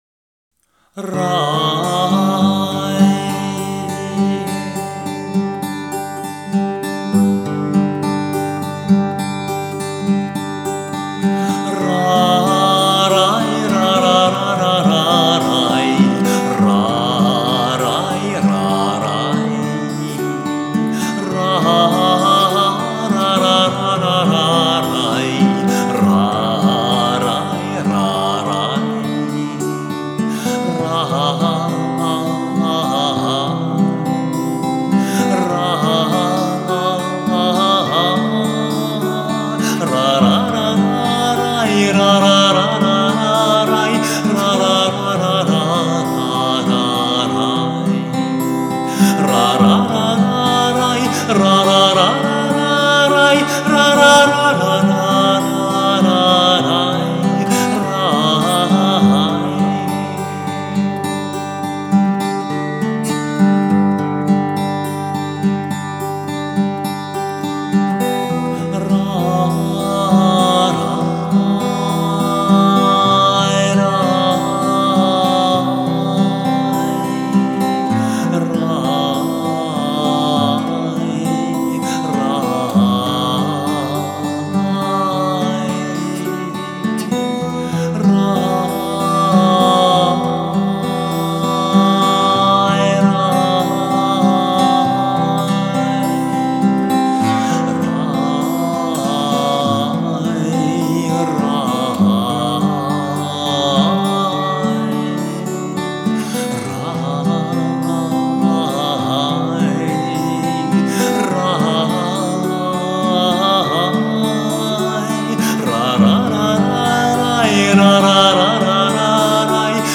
арт - классик - бард